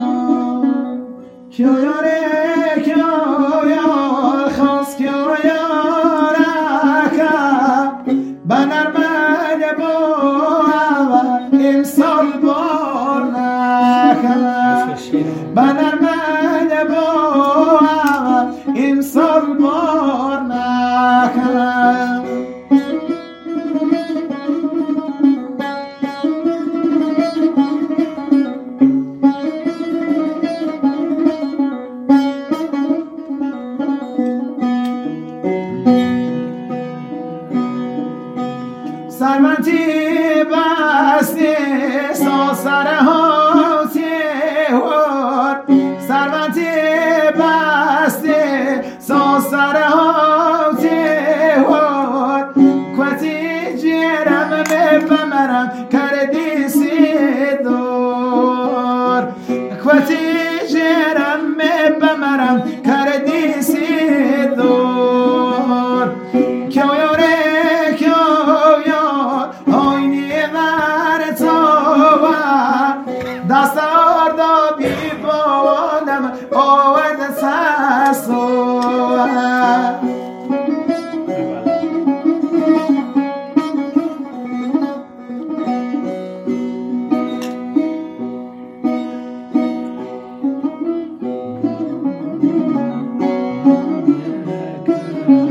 اهنگ شاد لکی و لری با ارگ